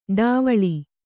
♪ ḍāvaḷi